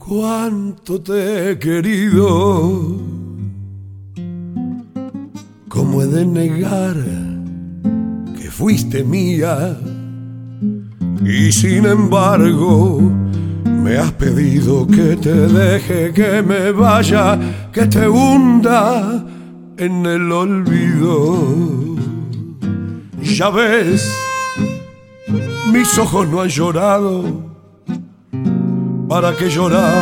voix
harmonica
guitare